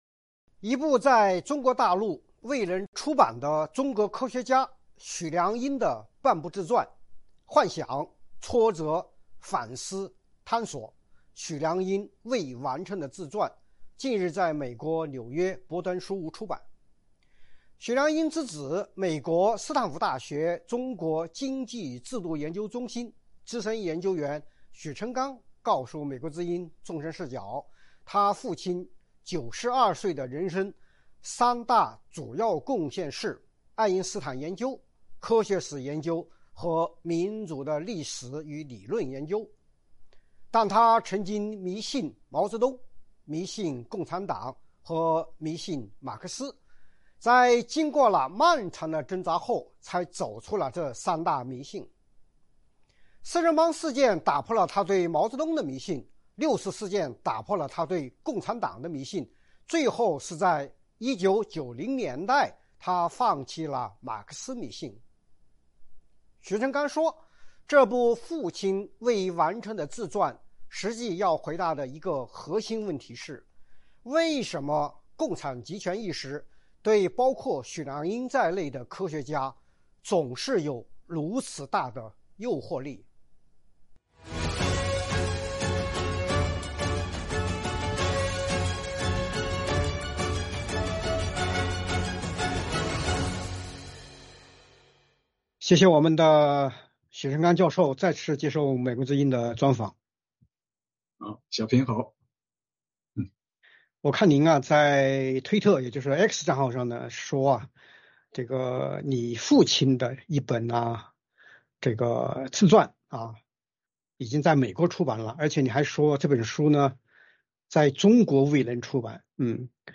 专访：许良英的幻想与挣扎：从迷信毛泽东共产党到回归爱因斯坦